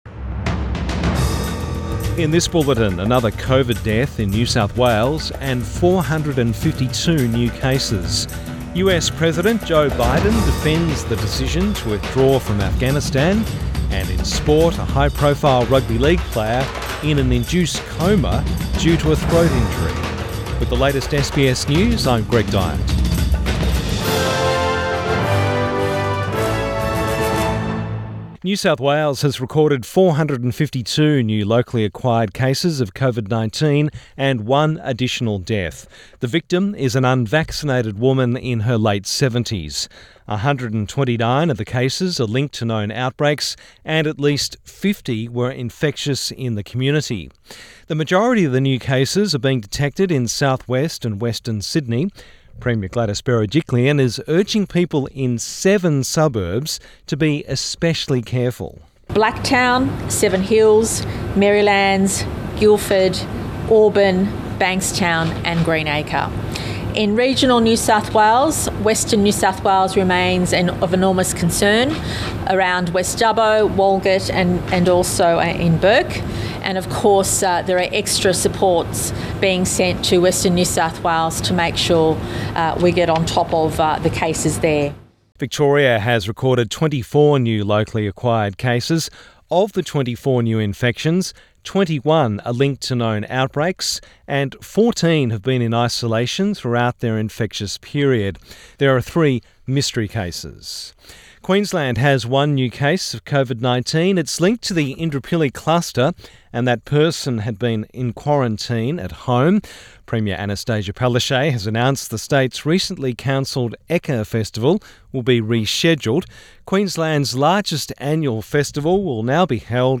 Midday bulletin 17 August 2021